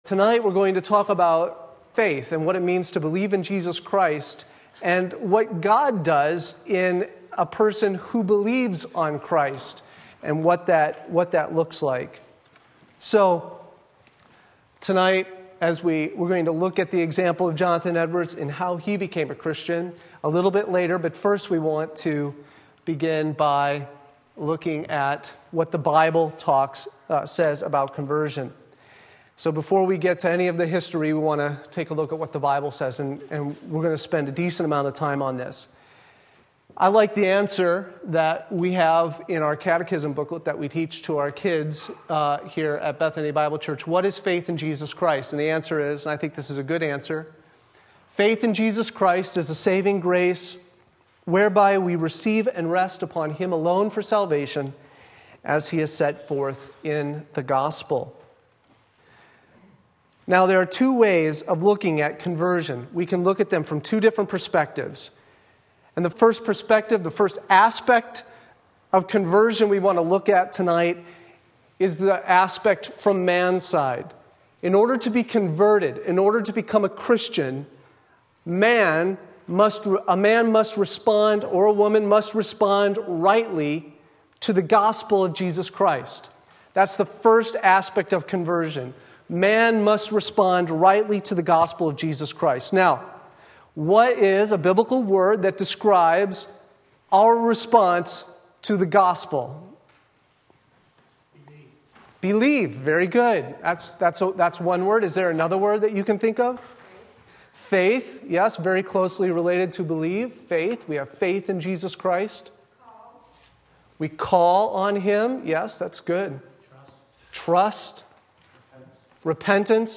Sermon Link
How Jonathan Edwards Became a Christian John 1:12 Wednesday Evening Service